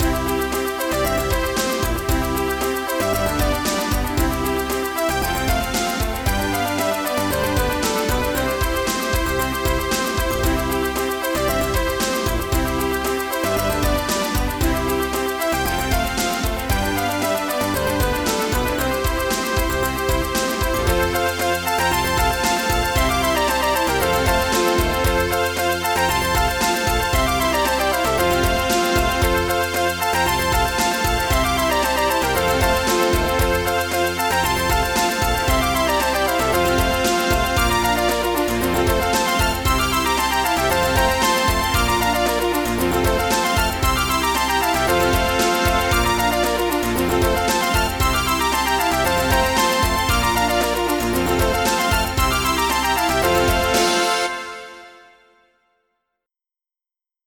My weakness for cute music is undeniable...!